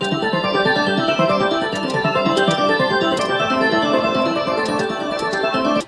slotmachine.ogg